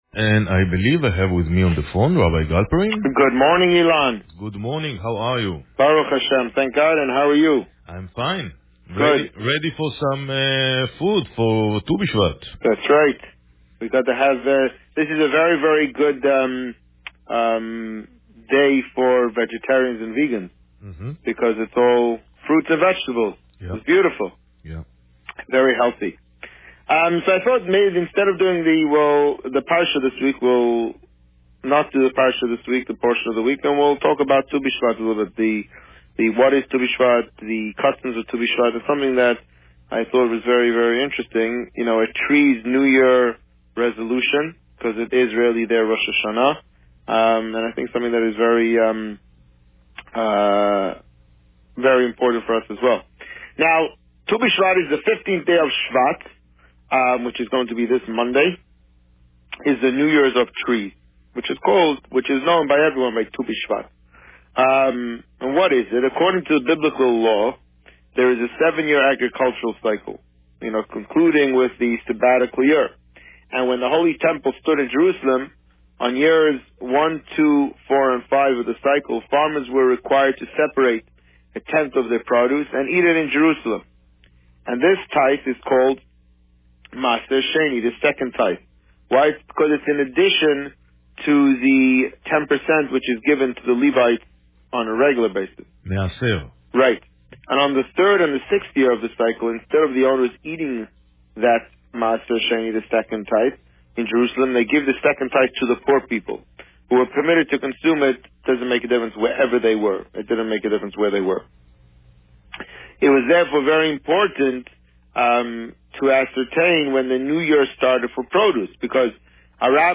This week, the Rabbi spoke about Tu B'Shevat. Listen to the interview here.